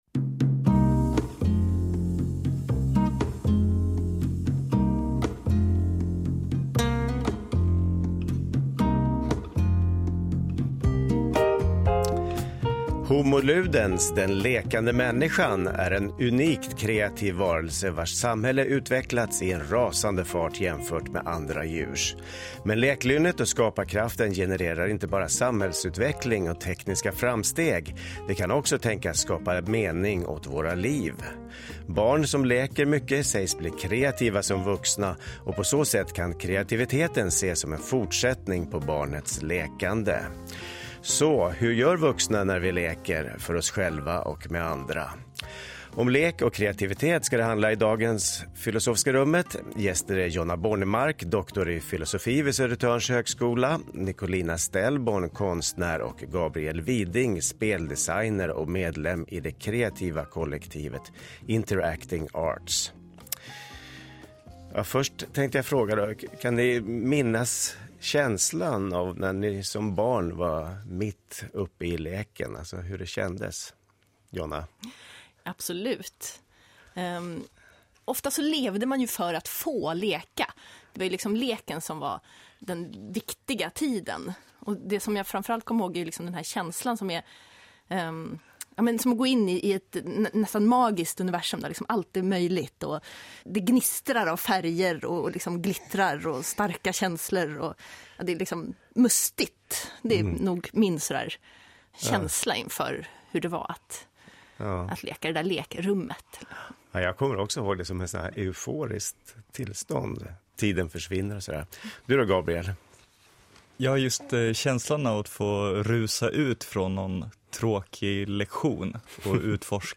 Det var ett bra samtal som kretsade kring lek och kreativitet i tanke och handling. Själv tycker jag att det är lite läskigt att höra min egen röst men stämningen i studion var fin och välkomnande. Jag försökte prata lite om sociala överenskommelser, improvisation och bejakande. Jag cirklar även kring skillnader mellan lek och spel.